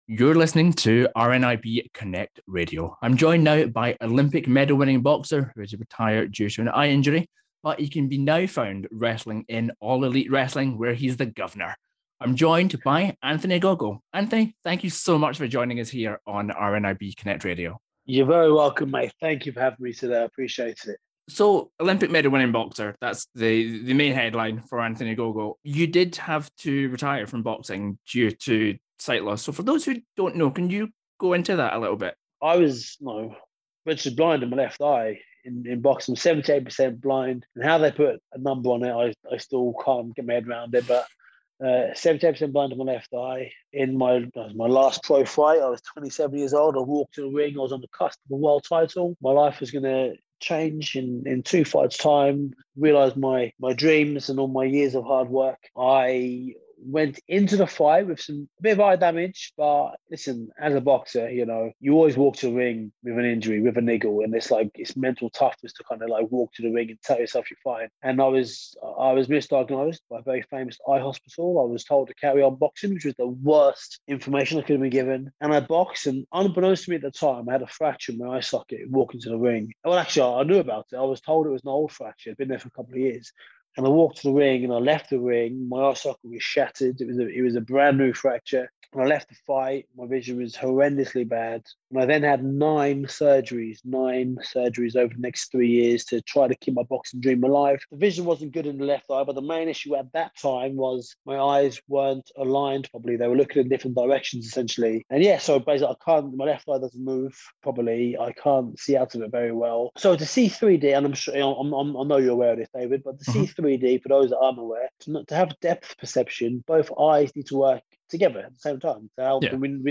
Anthony Ogogo Interview